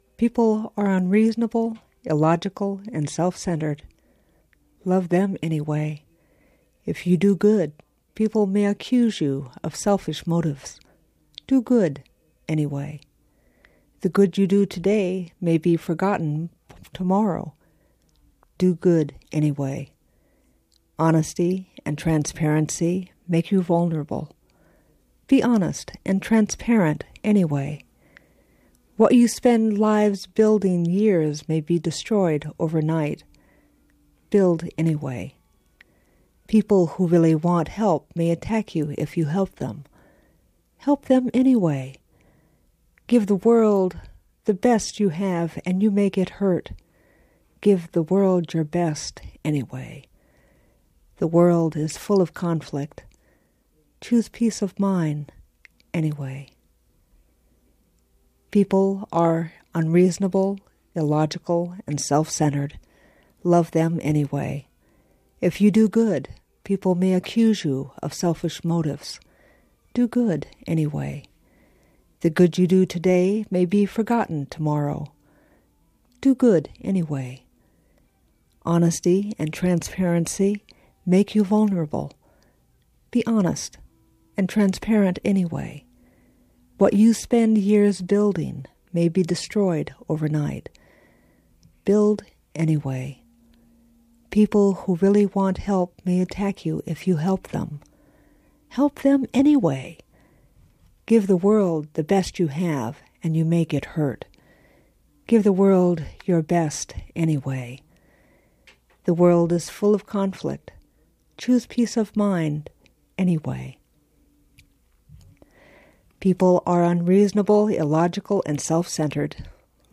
read this poem on KMUD in 1999 to urge us to “be kind anyway."